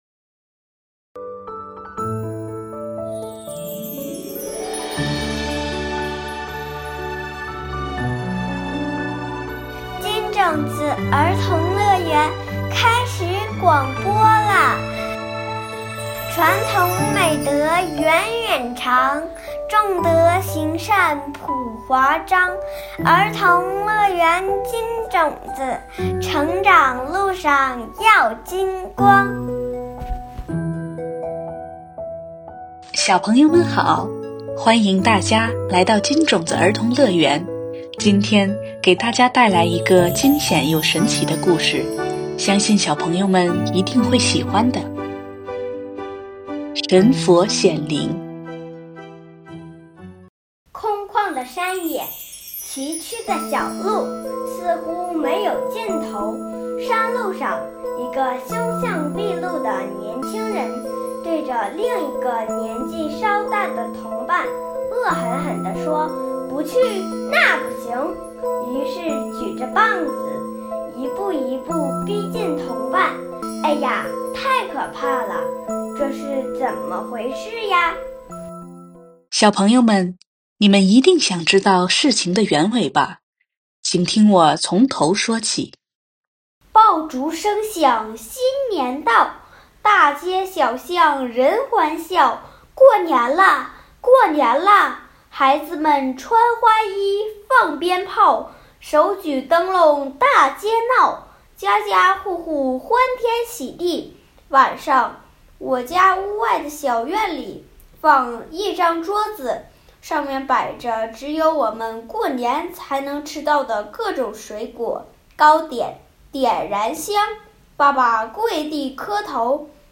金種子兒童樂園系列廣播故事（音頻）：第七期《神佛顯靈》上